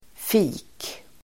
Ladda ner uttalet
fik substantiv (vardagligt), café [informal]Uttal: [fi:k] Böjningar: fiket, fik, fikenSynonymer: café, kaféDefinition: kafé